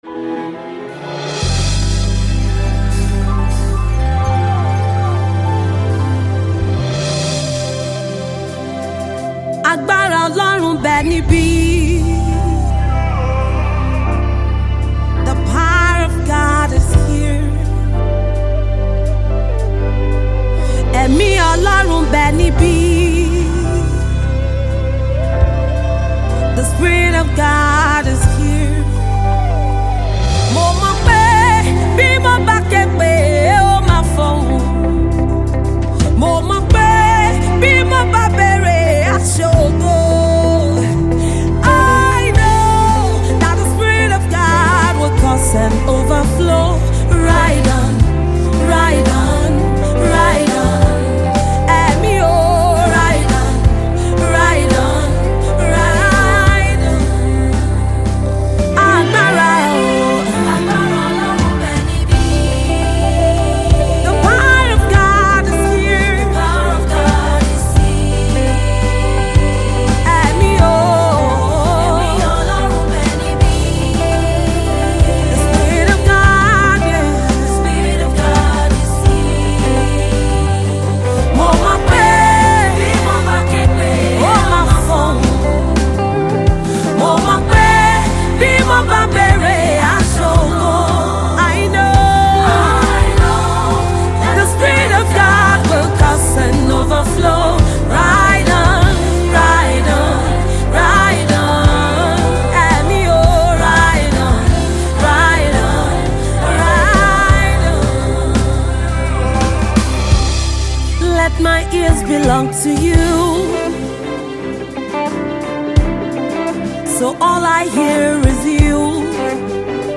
Gospel Songstress